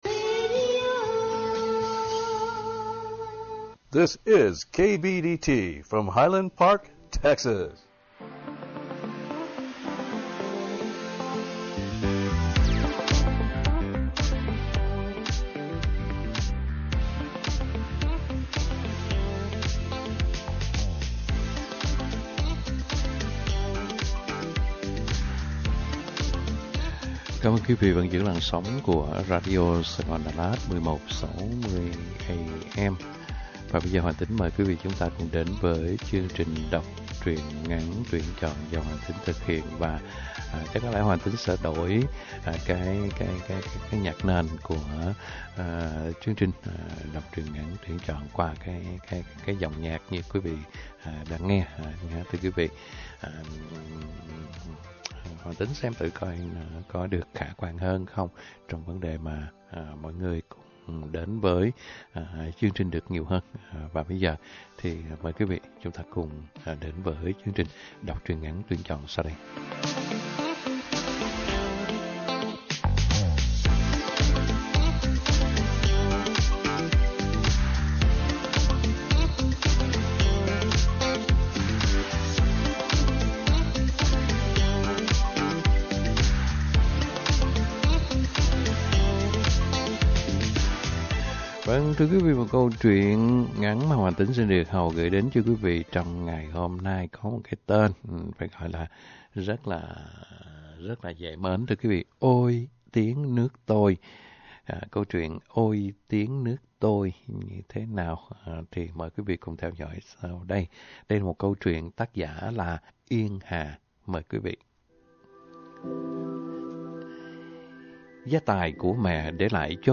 Đọc Truyện Ngắn = Ôi,Tiếng Nước Tôi !